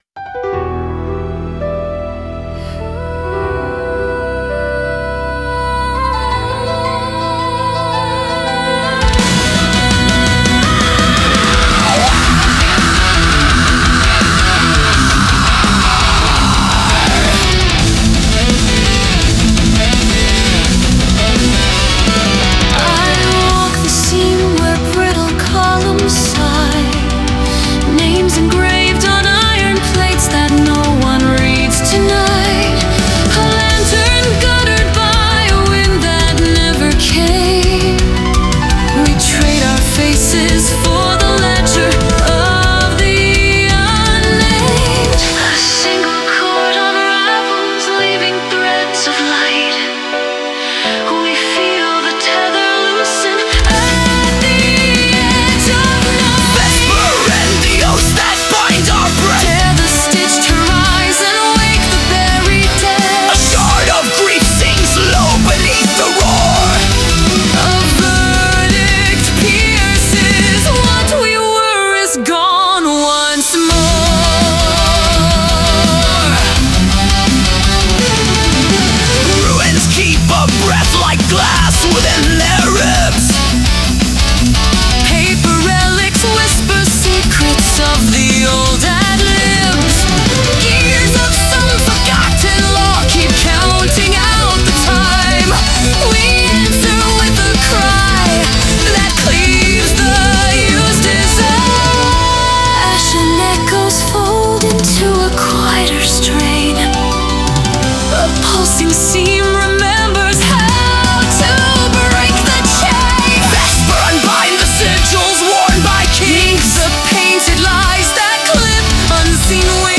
Metal Ballad